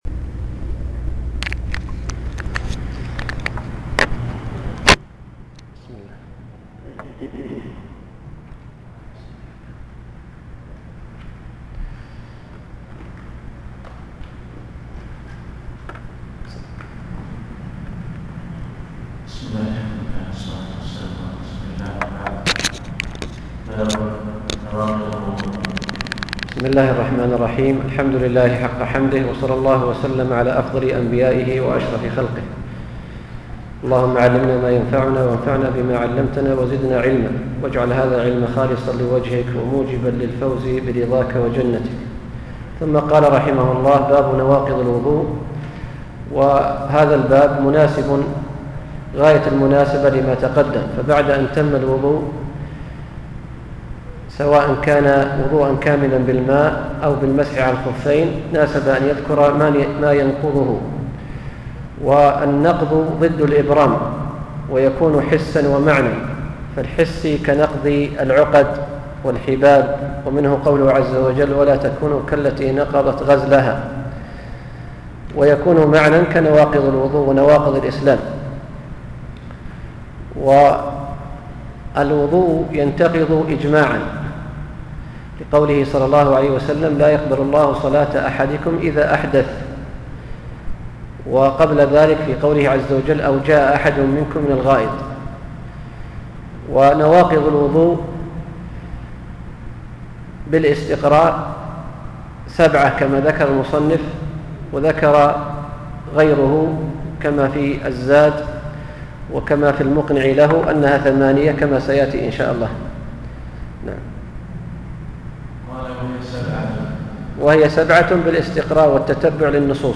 الدرس السادس: باب نواقض الوضوء – باب التيمم – باب الغسل من الجنابة